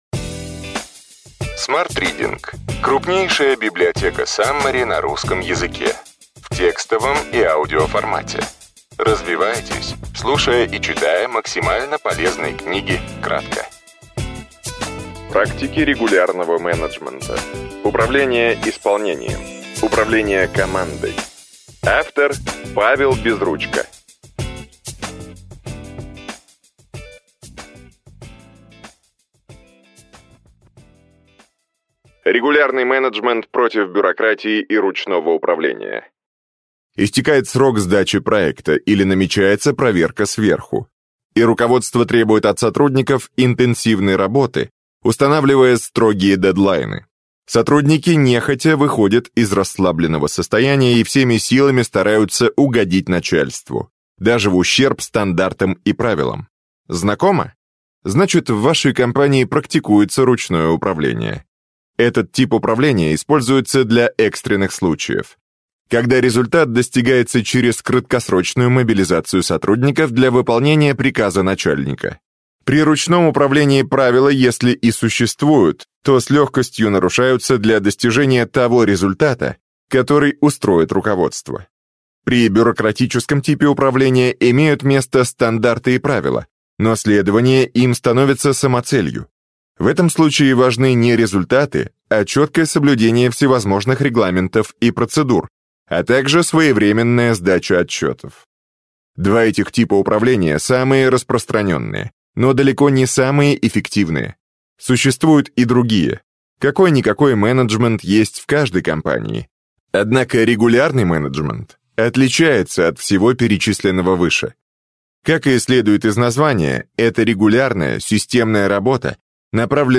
Аудиокнига Практики регулярного менеджмента. Управление исполнением. Управление командой.